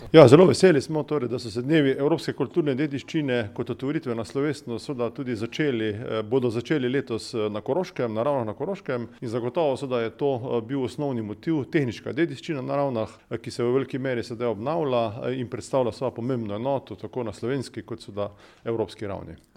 Letošnji dogodek so naslovili Zidovi naše preteklosti, temelji naše prihodnosti. Kaj gostovanje dogodka pomeni za Ravne na Koroškem, je pojasnil župan Tomaž Rožen:
izjava Rozen 1_2.mp3